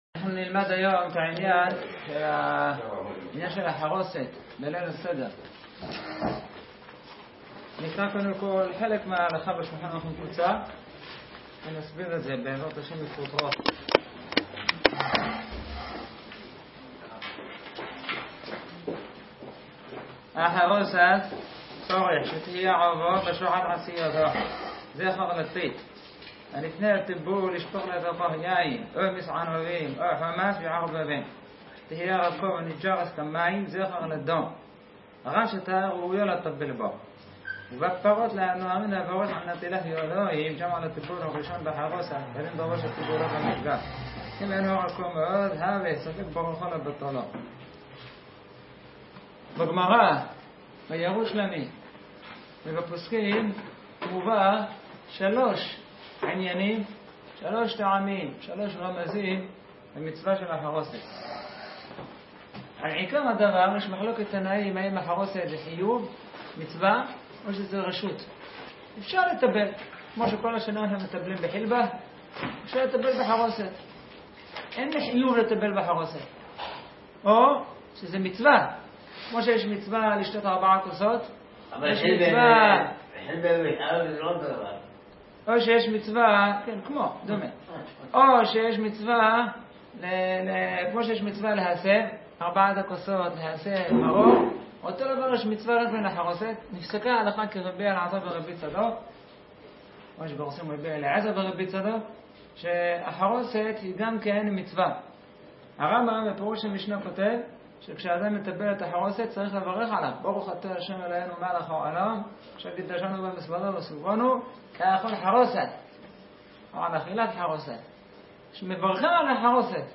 שיעור בעניין החרוסת לליל הסדר